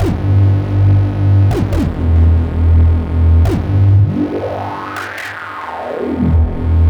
Index of /90_sSampleCDs/Club_Techno/Bass Loops
BASS_139_E.wav